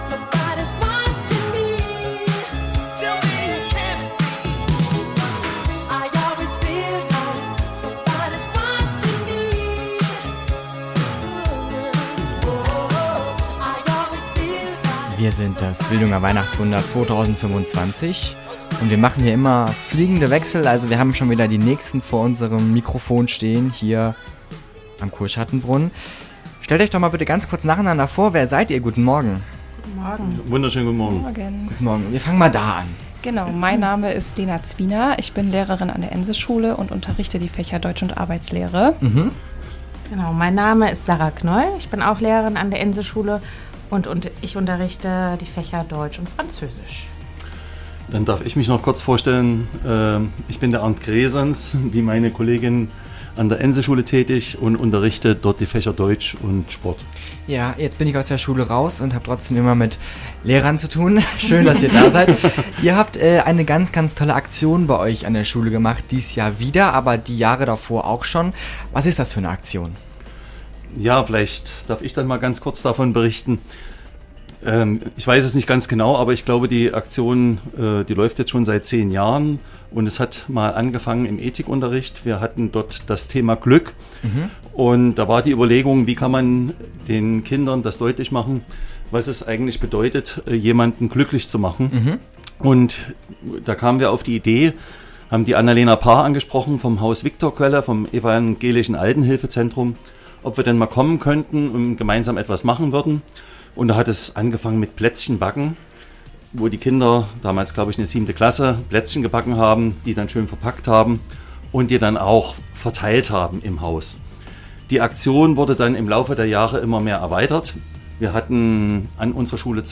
Hier ist der komplette Rradiomitschnitt: